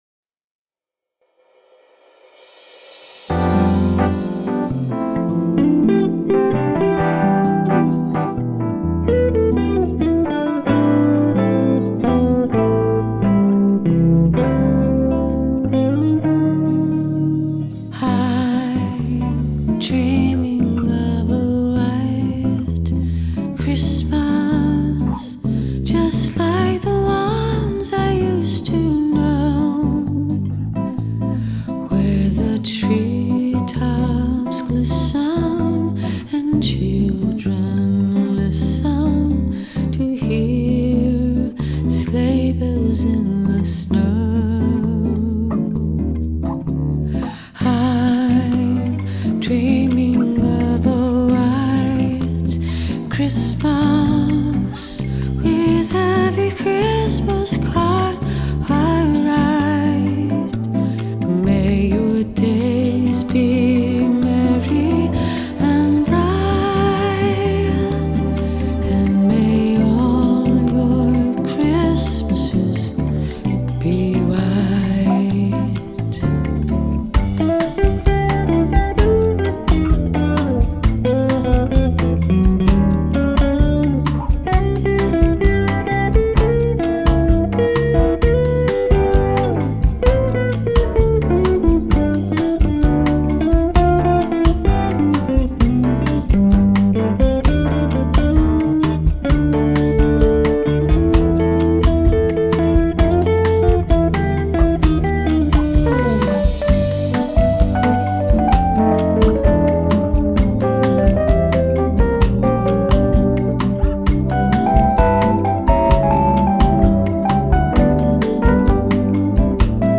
Genre: Bossa Nova Christmas